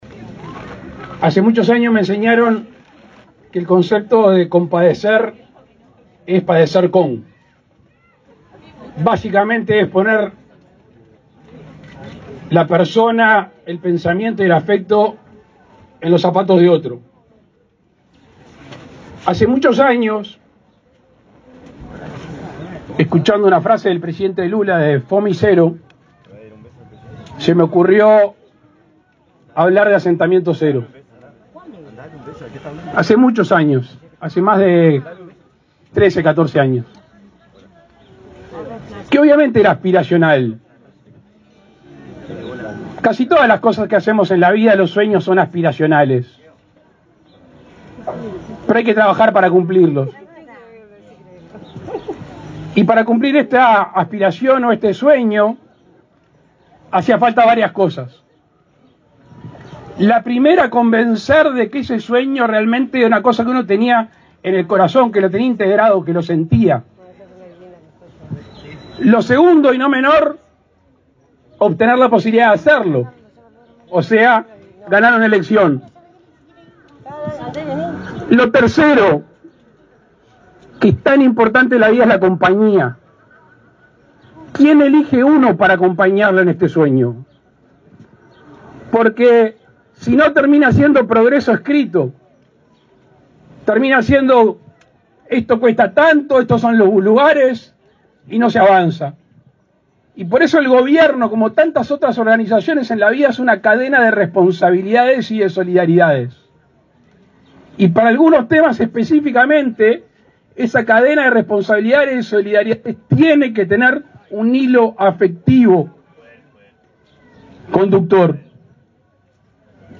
Palabras del presidente Luis Lacalle Pou
Este jueves 17, el presidente de la República, Luis Lacalle Pou, encabezó un acto de entrega de viviendas en Paysandú, en el marco del plan Avanzar.